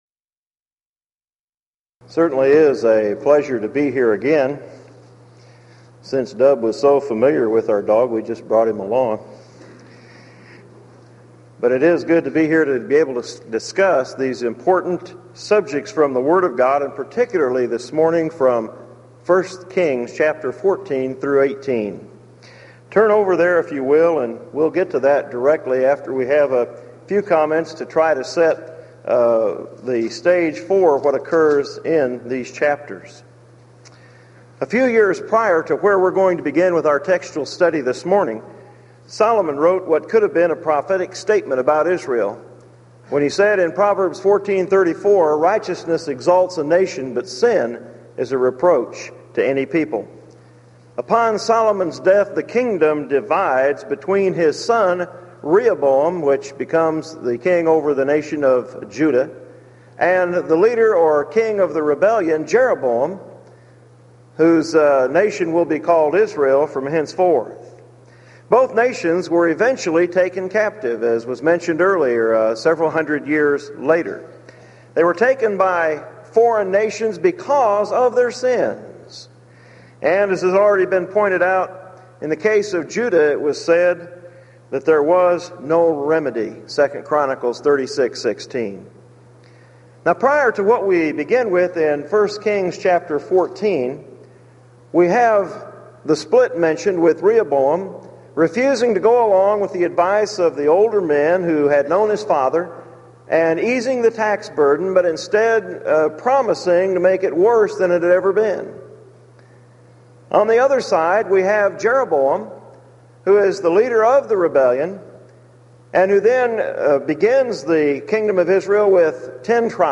Series: Denton Lectures